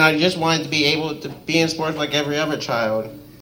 During Monday night’s opening ceremony